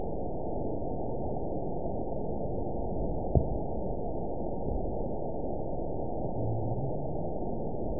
event 920398 date 03/23/24 time 00:04:31 GMT (1 year, 1 month ago) score 8.72 location TSS-AB02 detected by nrw target species NRW annotations +NRW Spectrogram: Frequency (kHz) vs. Time (s) audio not available .wav